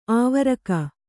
♪ āvaraka